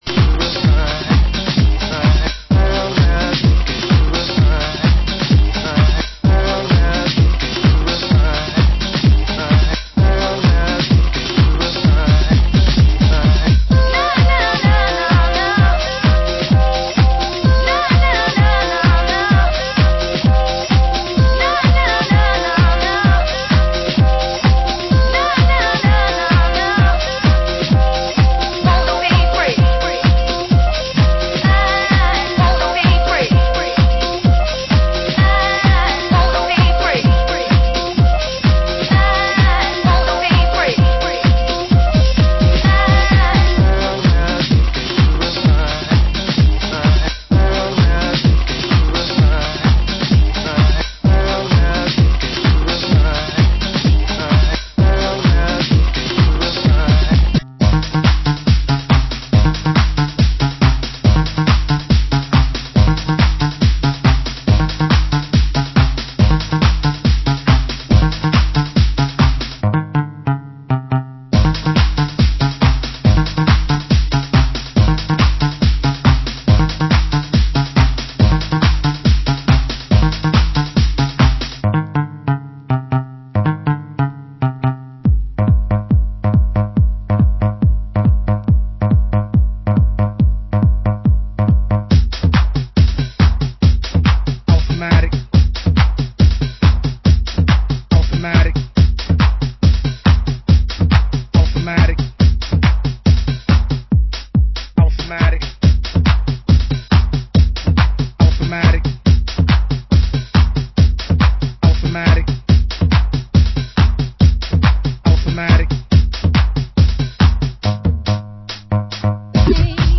Genre: Ghetto Tech